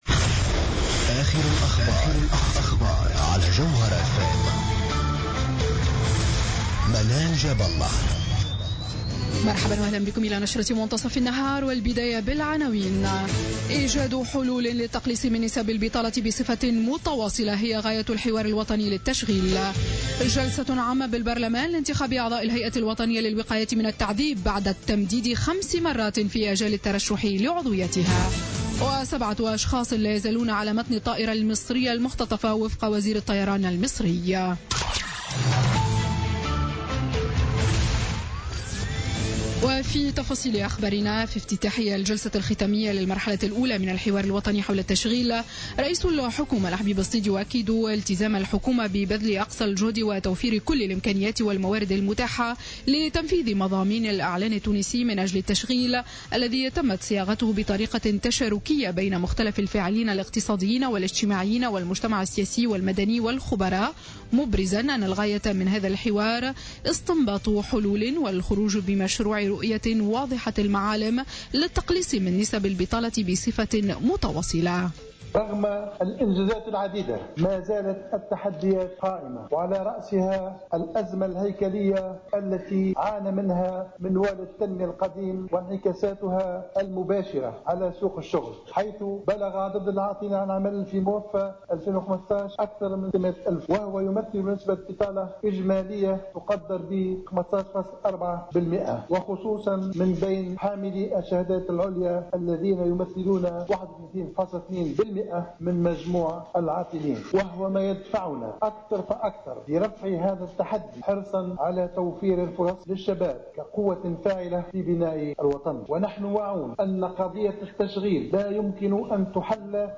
نشرة أخبار منتصف النهار ليوم الثلاثاء 29 مارس 2016